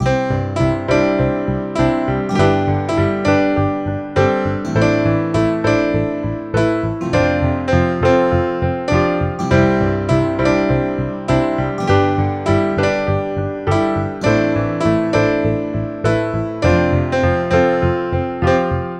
Lesson 3: Creating a Pop Song
LI: We are learning to create a pop song using common chords.
lesson-3-example-pop.wav